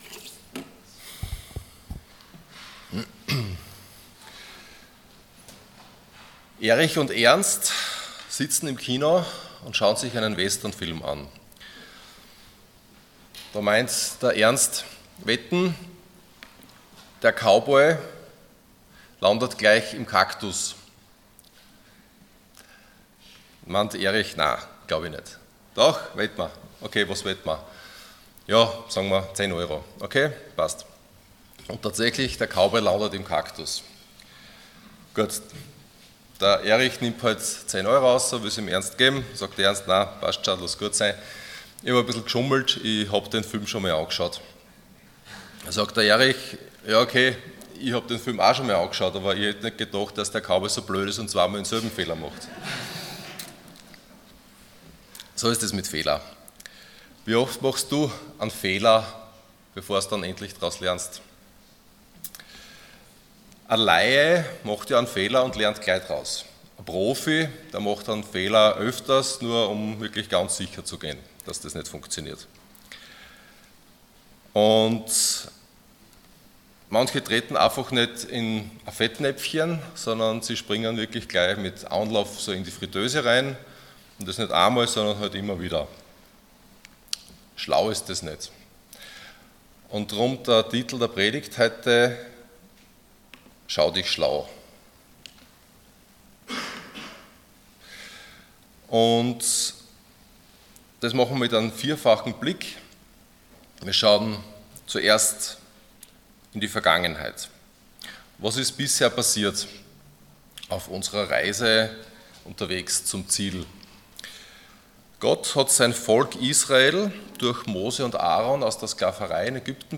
20.07.2025 Schau dich schlau Prediger